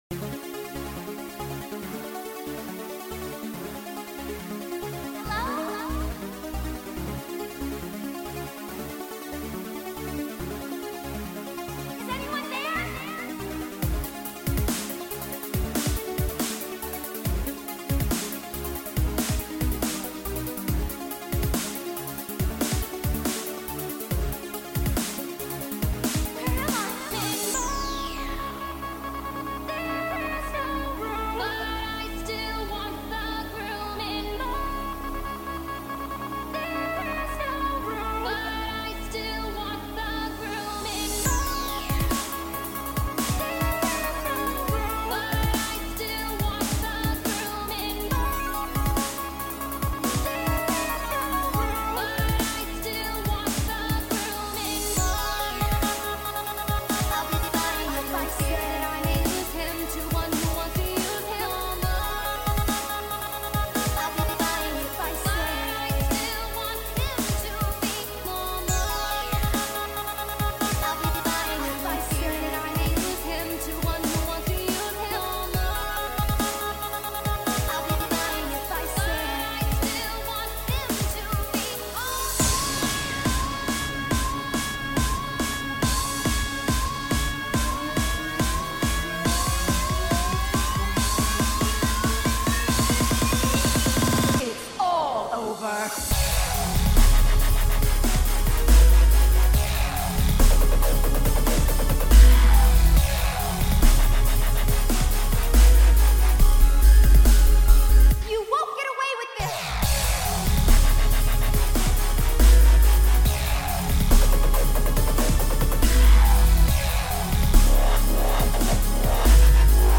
dubstep remix
genre:dubstep